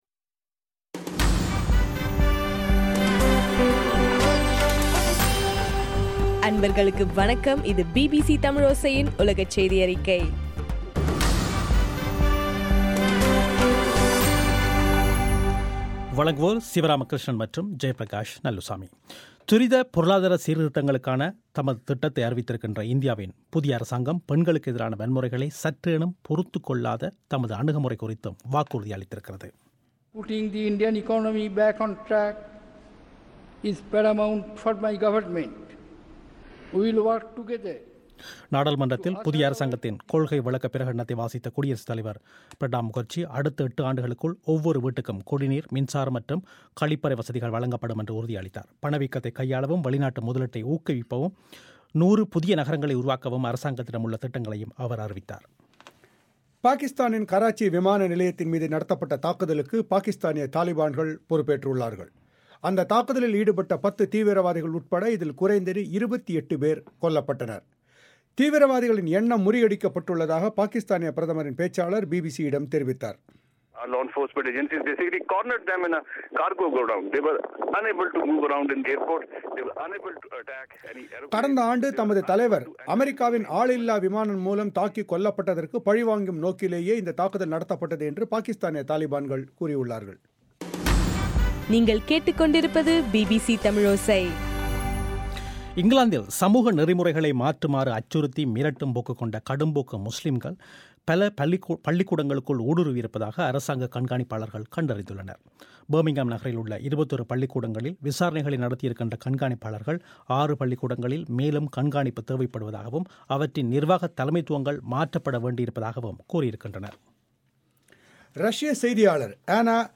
தமிழோசை செய்தியறிக்கை - 09/06/2014 திங்கட்கிழமை